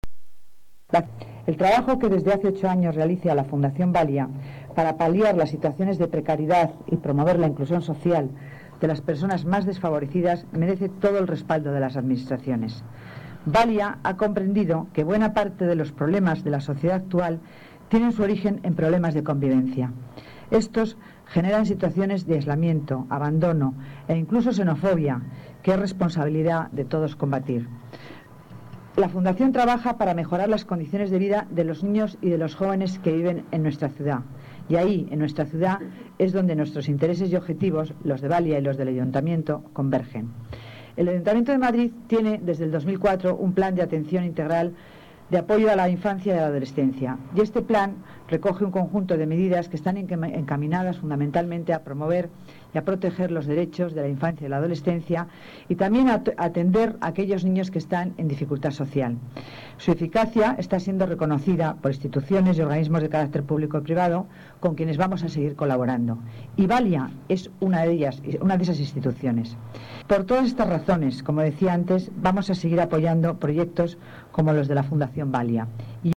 Nueva ventana:Declaraciones de la delegada de Familia y Servicios Sociales, Concepción Dancausa